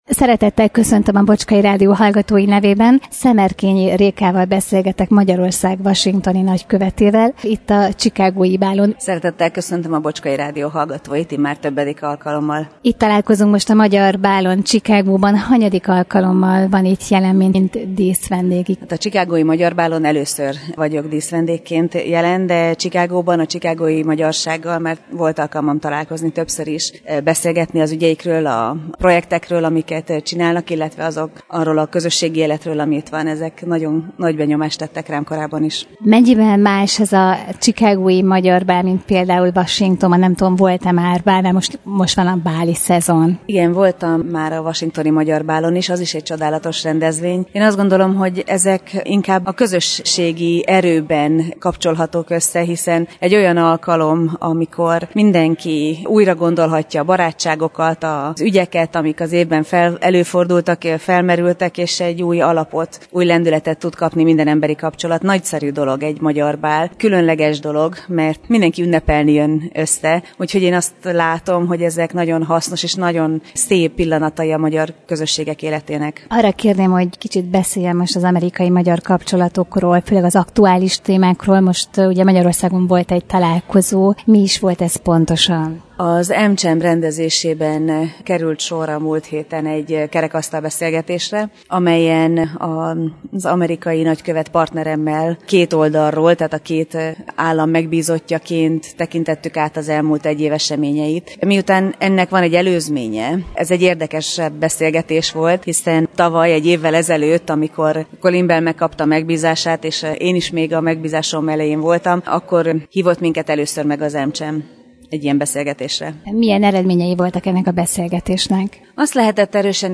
Szemerkényi Réka Magyarország washingtoni nagykövete a Chicagói Magyar Bálon – Bocskai Rádió
SzemerkenyiReka-ChicagoiBal.mp3